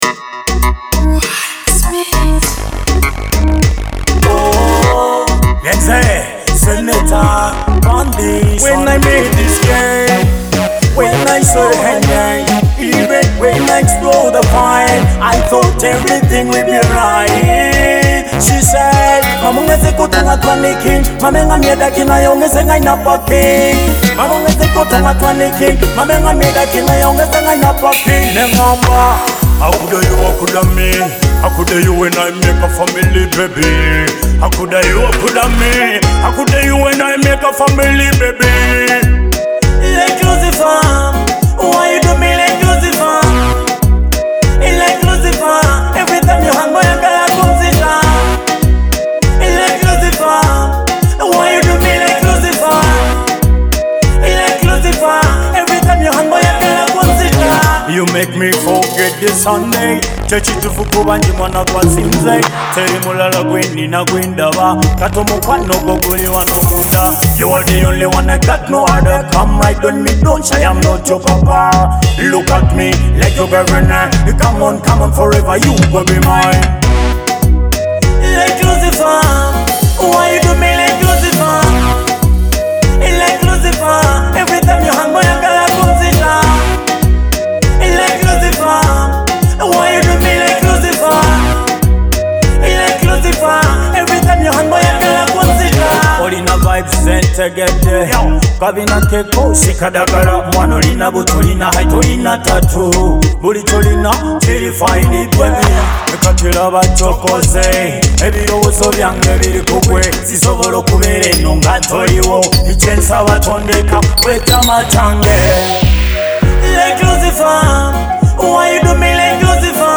blending soulful vocals and deep rhythms